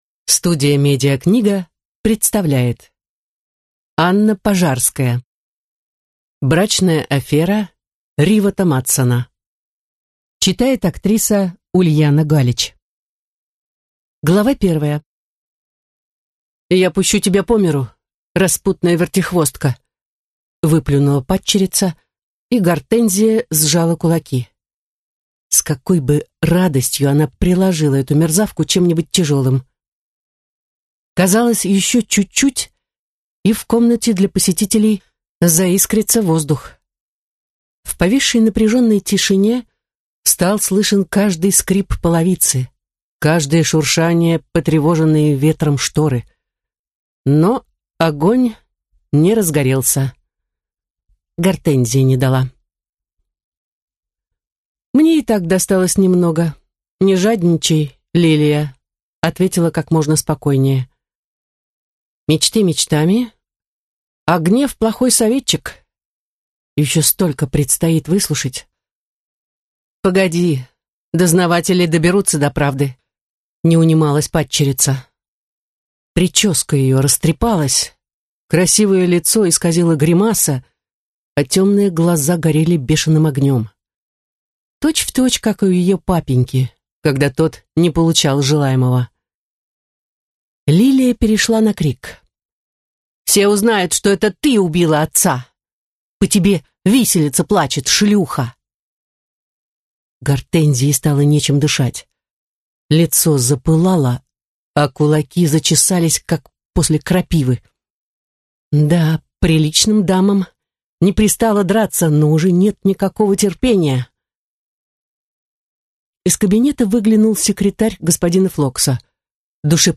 Аудиокнига Брачная афера Ривотта Мадсона | Библиотека аудиокниг